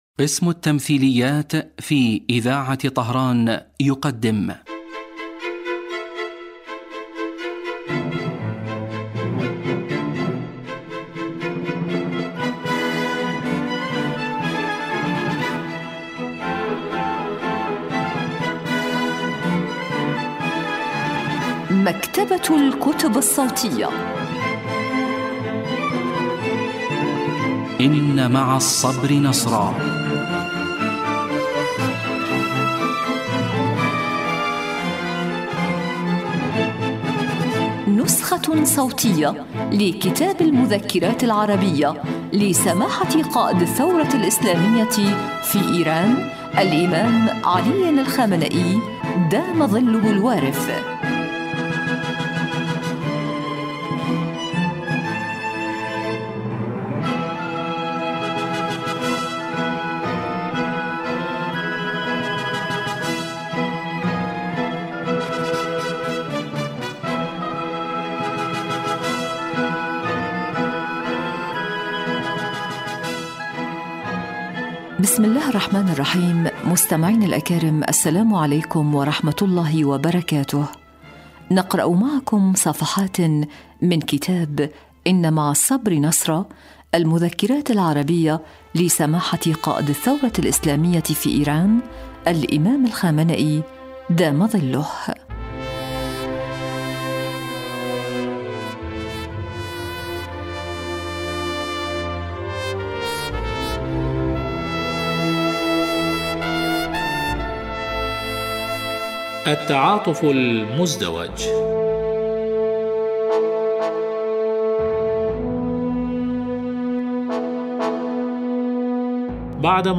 إذاعة طهران- إن مع الصبر نصرا: نسخة صوتية لكتاب المذكرات العربية لقائد الثورة الإسلامية الإمام الخامنئي (دام ظله).